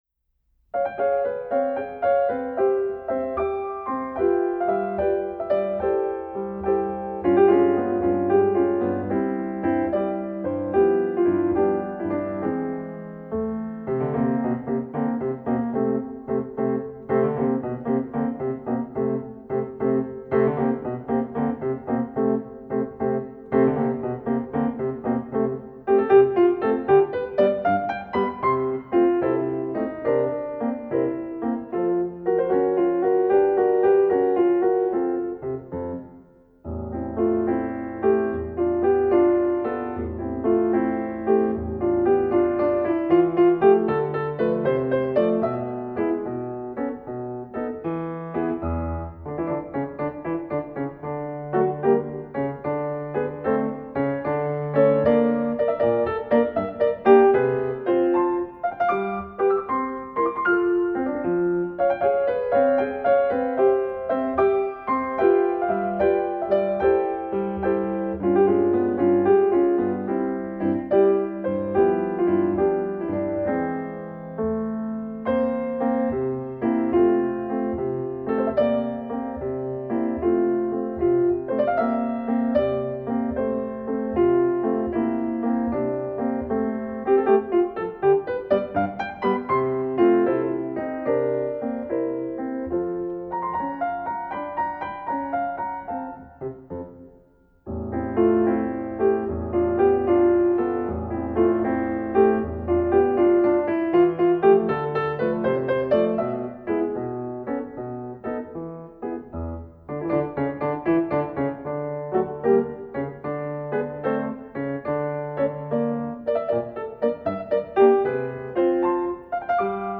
Voicing: High Voice Collection